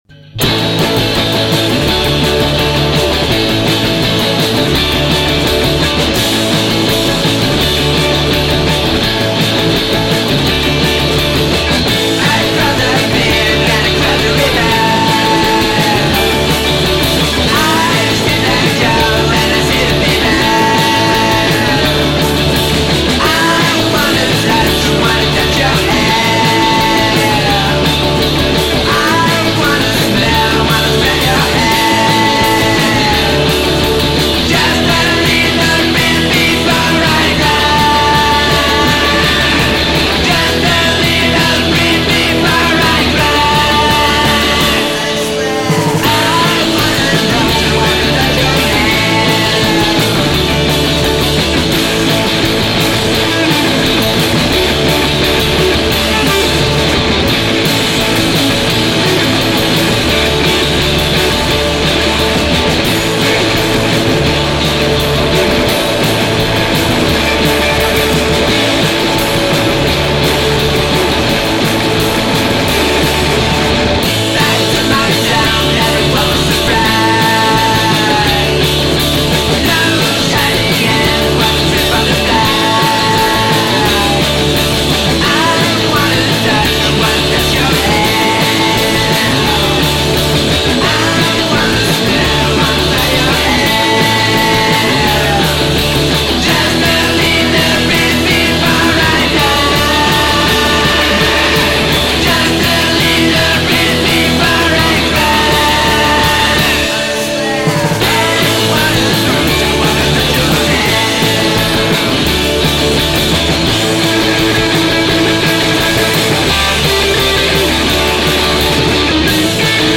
oltre a garage e psichedelia